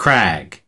Транскрипция и произношение слова "crag" в британском и американском вариантах.